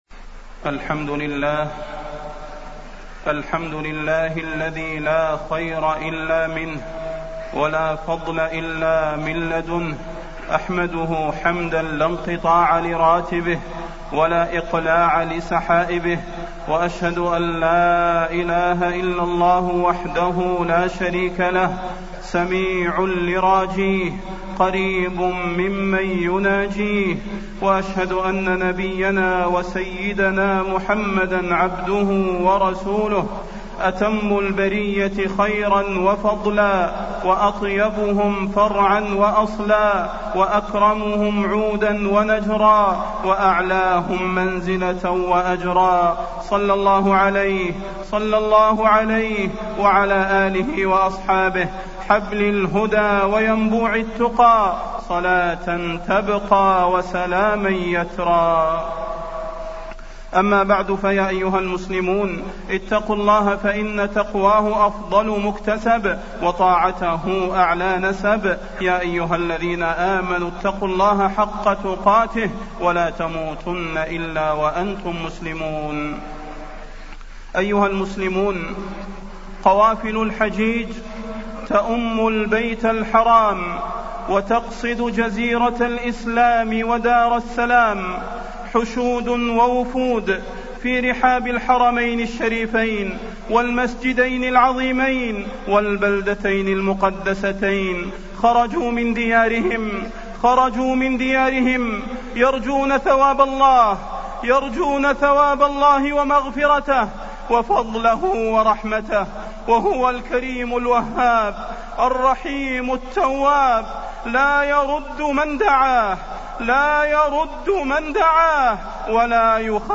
تاريخ النشر ٢٧ ذو القعدة ١٤٢٨ هـ المكان: المسجد النبوي الشيخ: فضيلة الشيخ د. صلاح بن محمد البدير فضيلة الشيخ د. صلاح بن محمد البدير يا قوافل الحجيج